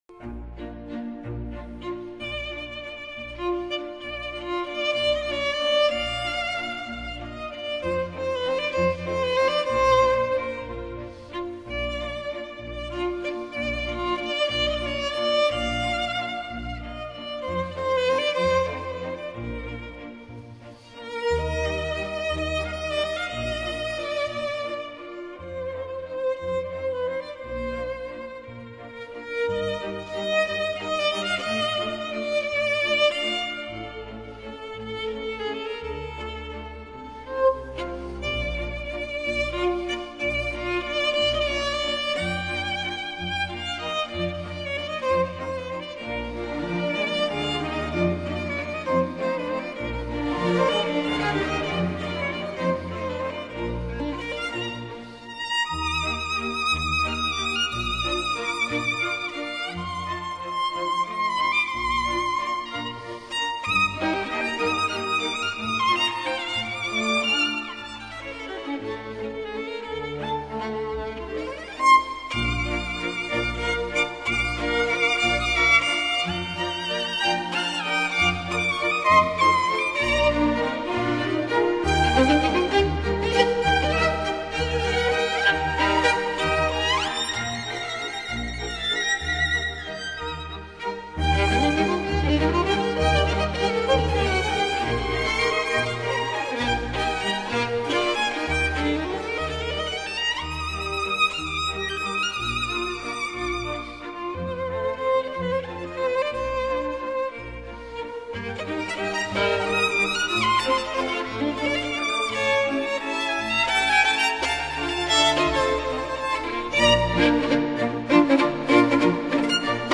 Allegretto    [0:02:30.60]